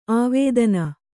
♪ āvēdana